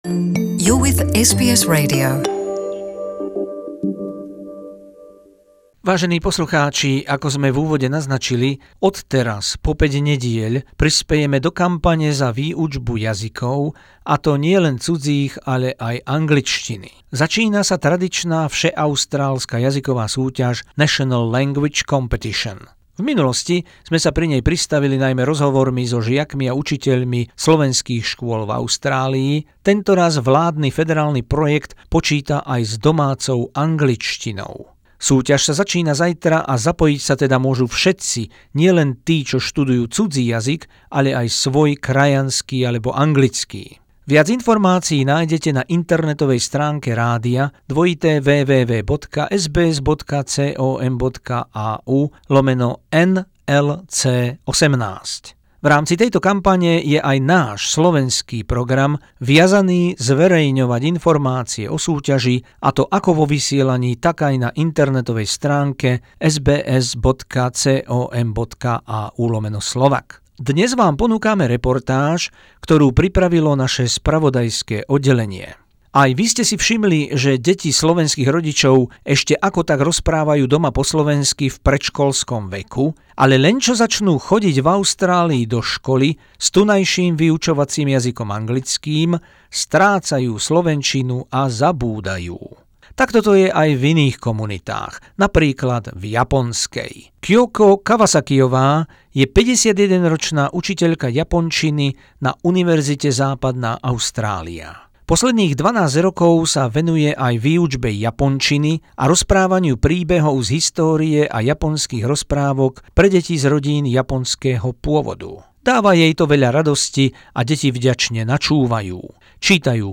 Dnes vám ponúkame reportáž, ktorú pripravilo naše spravodajské oddelenie o jednej iniciatíve univerzity Monash v Melbourne.